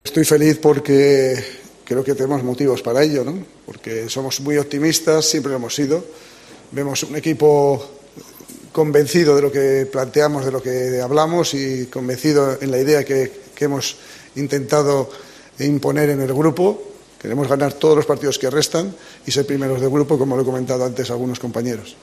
AUDIO: El entrenador de la Selección Española ha hablado después de la goleada de este martes (6-0) a Chipre en la fase clasificatoria de la Eurocopa 2024